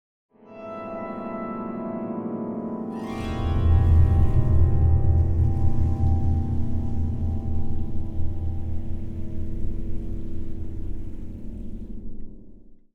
Sound Design
The sound of the work at this stage shows a subtle sense of calm, but this calmness always carries a hint of uneasiness. The subtle fluctuations and potential low frequencies symbolize the emotional residue that has not been completely eliminated in the heart.